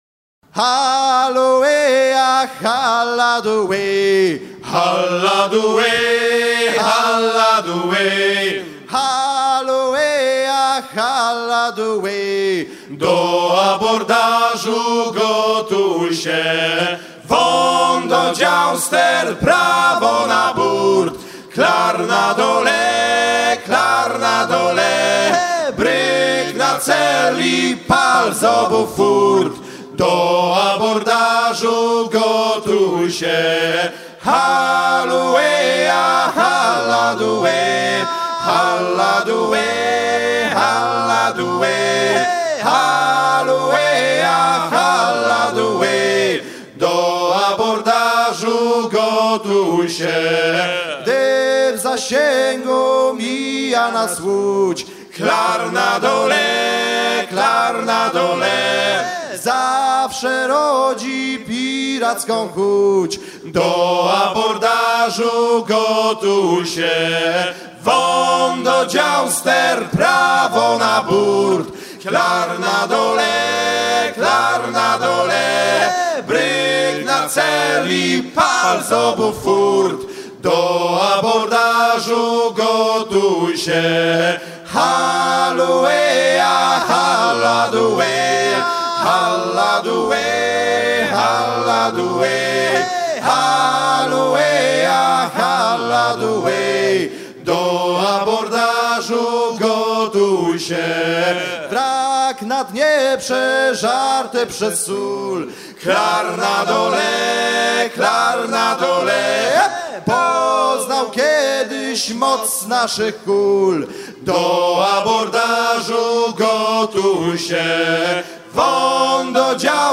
recueilli vers 1960 au Pollet quartier de Dieppe chanté par des chanteurs polonais
gestuel : à haler